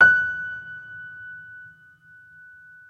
piano-sounds-dev
Vintage_Upright